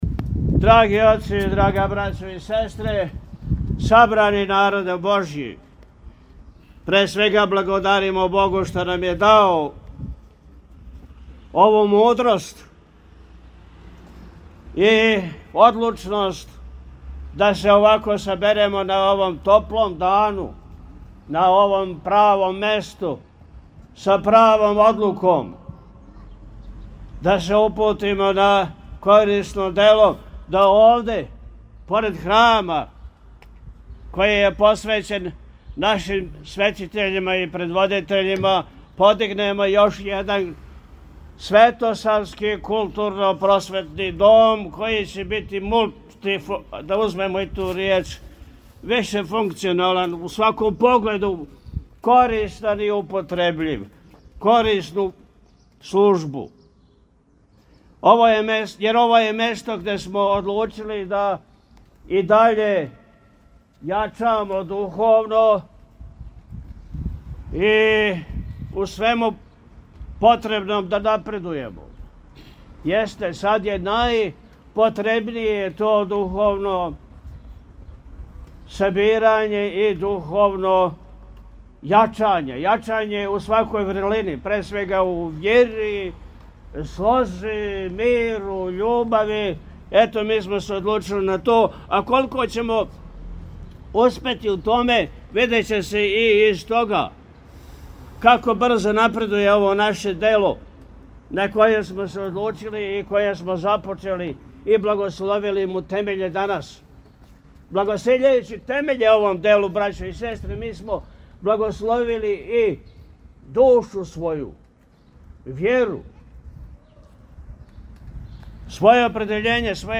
Његово Високопреосвештенство Архиепископ и Митрополит милешевски г. Атанасије освештао је уочи Видовдана, 27. јуна 2025. године, темеље будућег парохијског дома у порти храма Светог кнеза Лазара у Бродареву код Пријепоља.
Brodarevo-Osvecenje-temelja.mp3